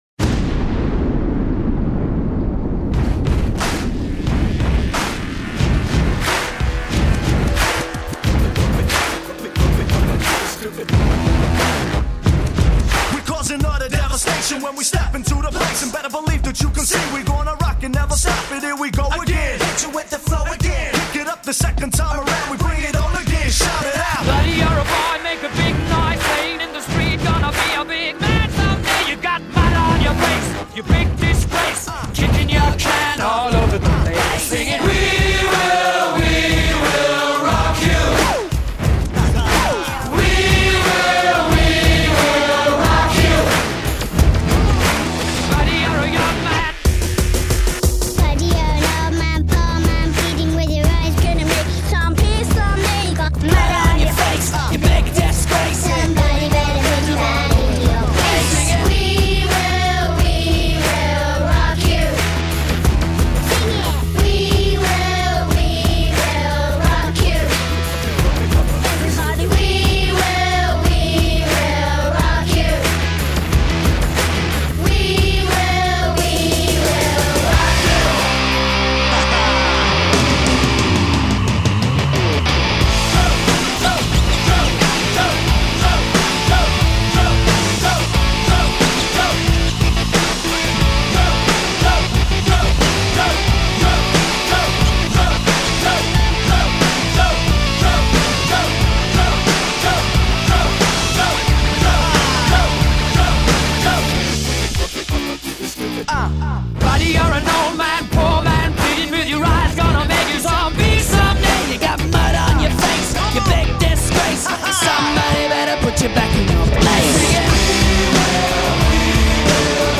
Kde je ten mix?